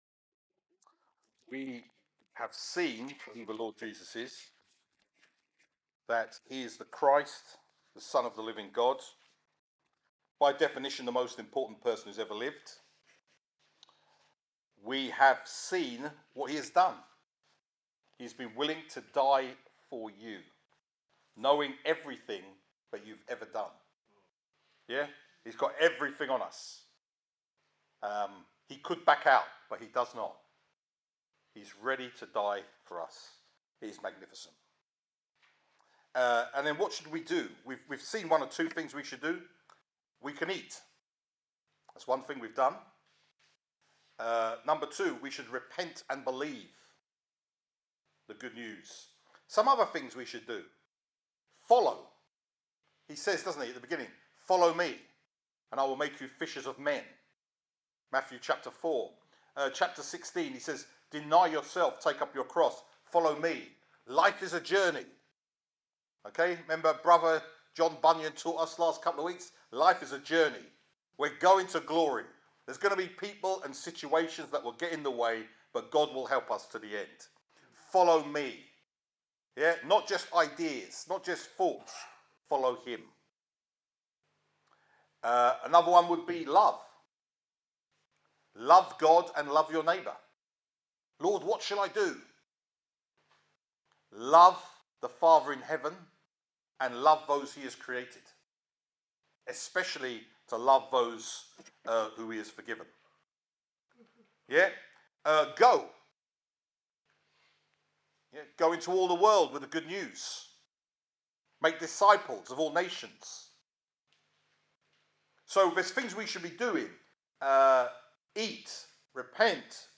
Sermon Part 2